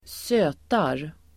Uttal: [²s'ö:tar]